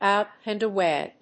óut and awáy